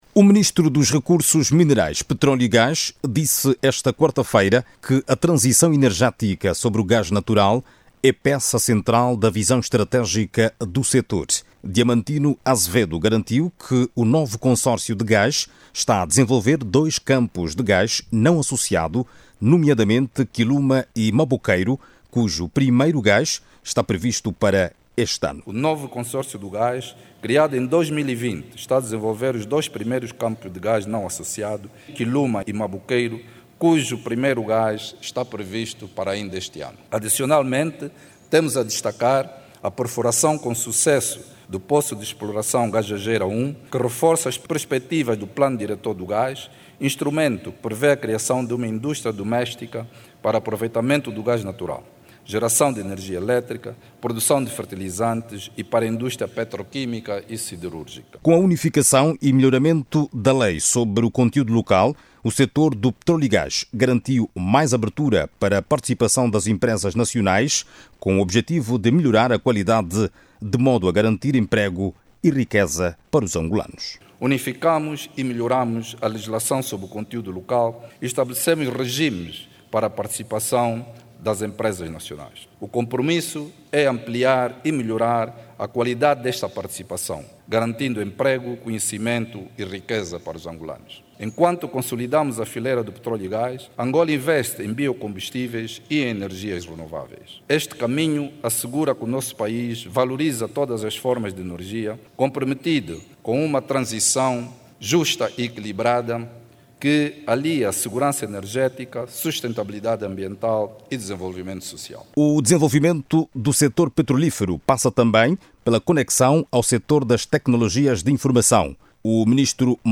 Este dado, foi avançado durante a sexta Conferência sobre Petróleo e Gás que decorre aqui em Luanda.